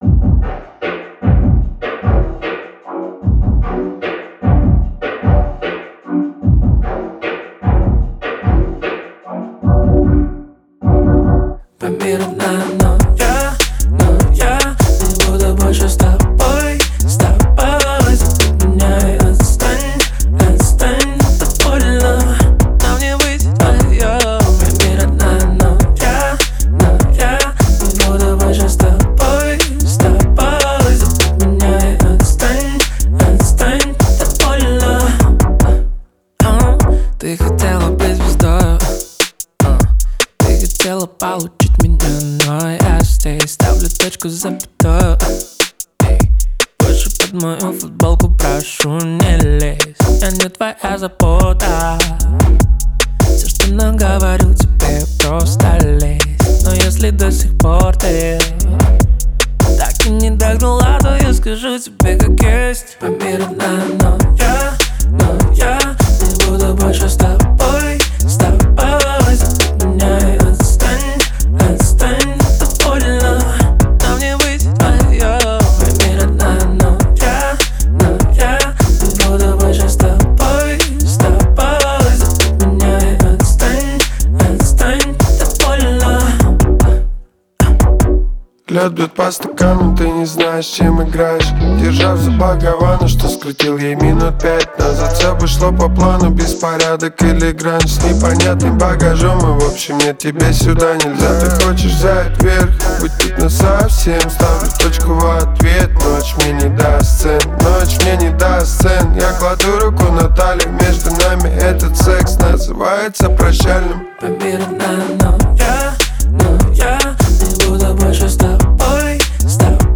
Русская поп музыка